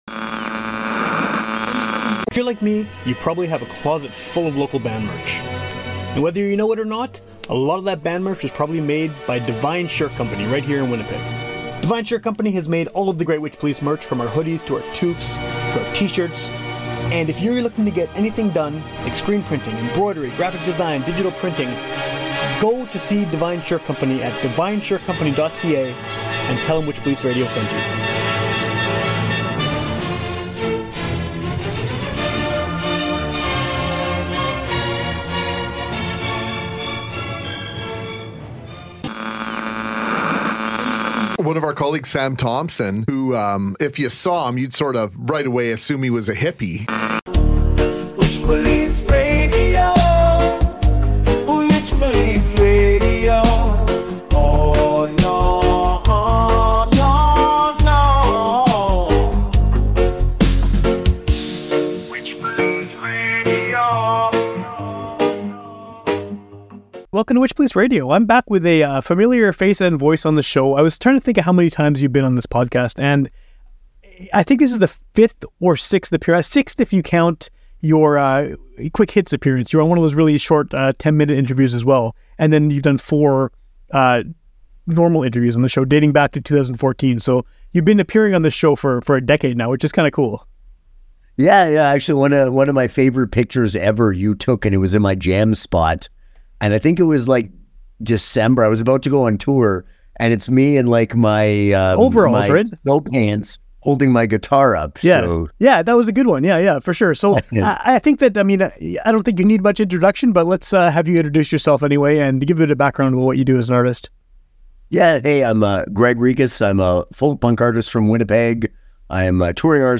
and he remains one of my favourite people to interview.